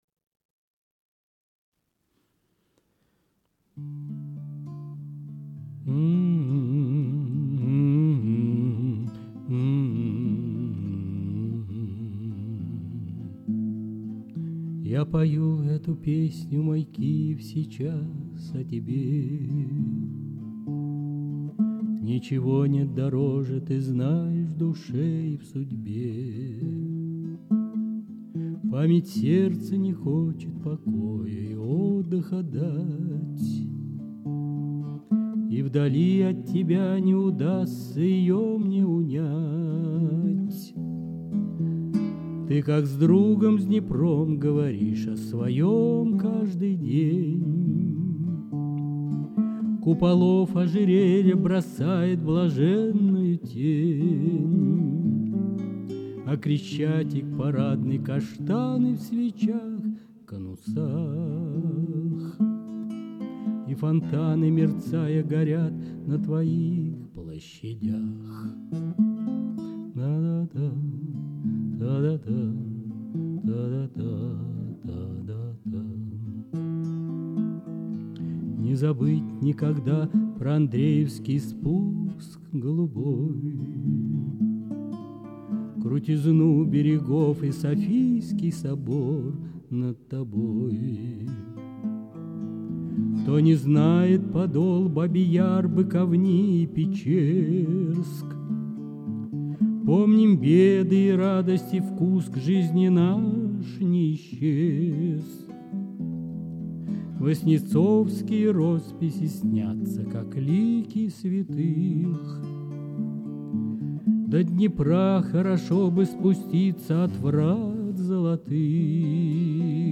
Music, vocal, guitar